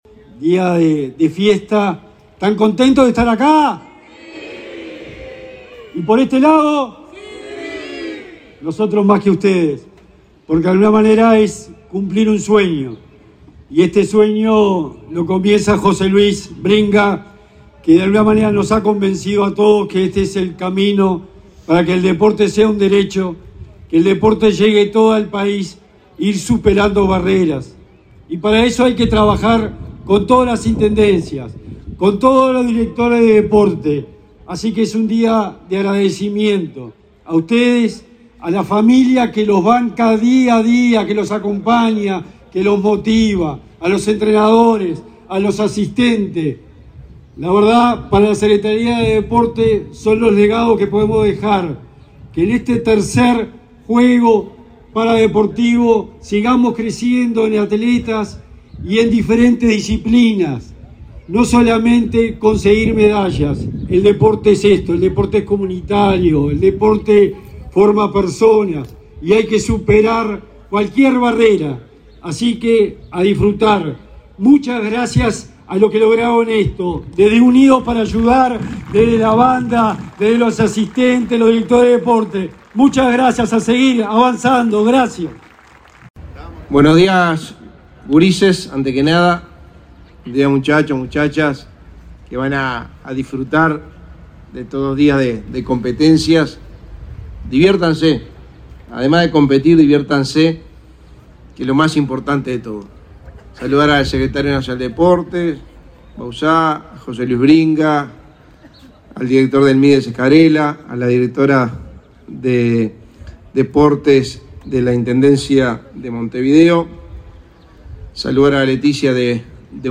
Palabras de autoridades en ceremonia inaugural de Juegos Paradeportivos
Palabras de autoridades en ceremonia inaugural de Juegos Paradeportivos 06/10/2023 Compartir Facebook X Copiar enlace WhatsApp LinkedIn El secretario nacional del Deporte, Sebastián Bauzá, y el secretario de la Presidencia, Álvaro Delgado, participaron en la ceremonia inaugural de los Juegos Paradeportivos Nacionales 2023, que se realizan en la pista de atletismo del Prado, en Montevideo.